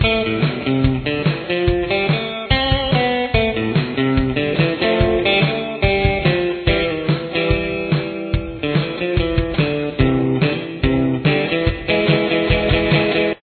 Tempo: 143 beats per minute
Key Signature: A minor
Lead Guitar